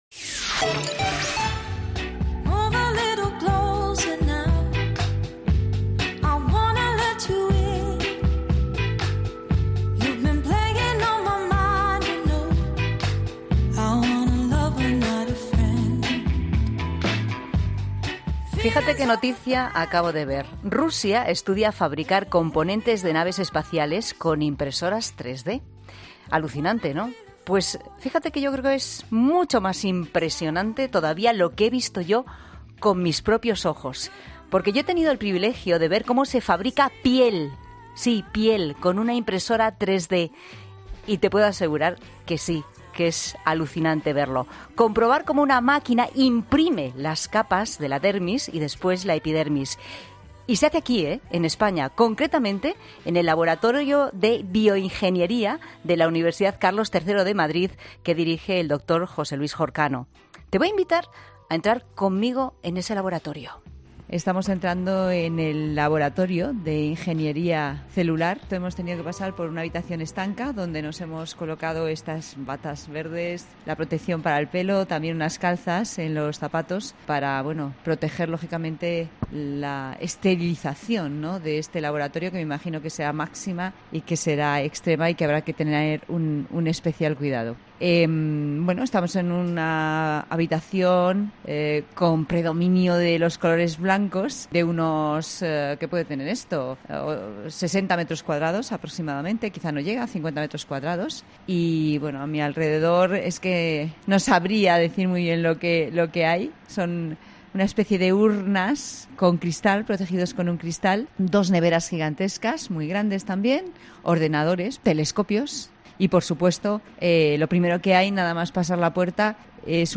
En La Tarde de COPE nos hemos trasladado hasta ese laboratorio